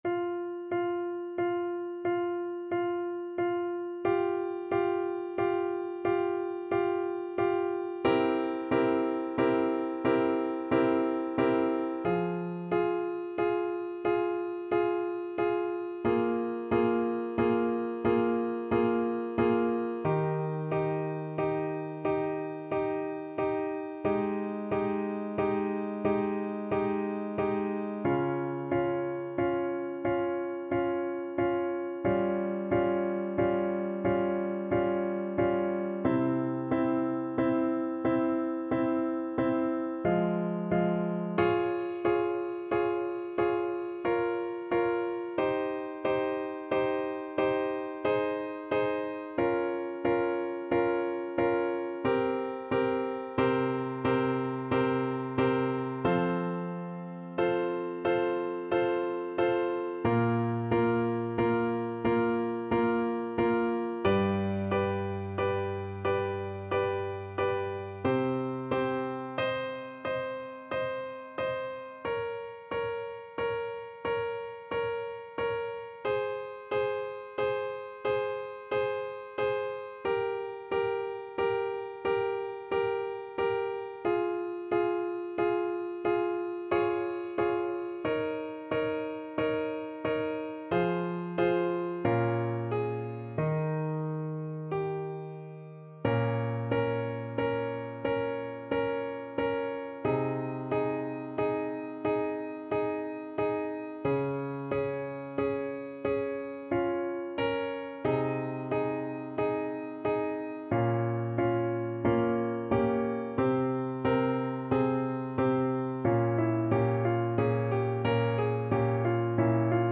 Play (or use space bar on your keyboard) Pause Music Playalong - Piano Accompaniment Playalong Band Accompaniment not yet available transpose reset tempo print settings full screen
Adagio =45
3/4 (View more 3/4 Music)
F minor (Sounding Pitch) D minor (Alto Saxophone in Eb) (View more F minor Music for Saxophone )
Classical (View more Classical Saxophone Music)